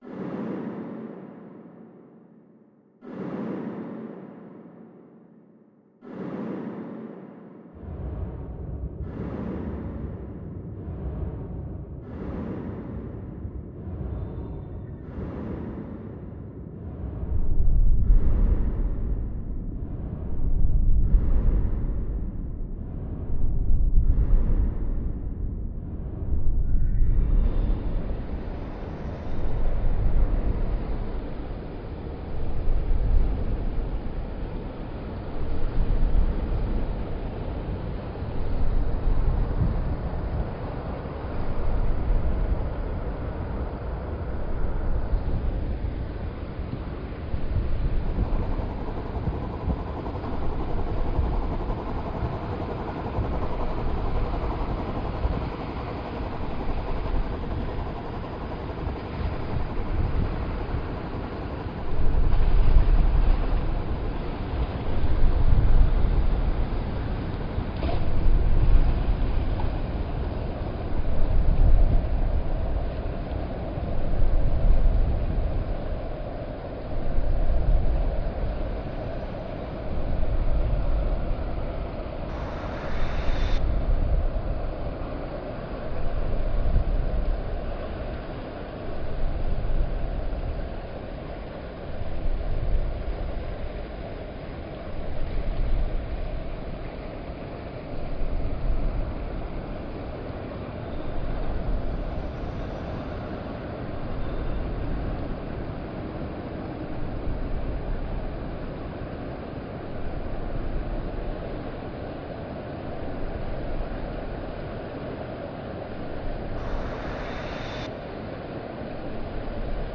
Poldhu Cove, reimagined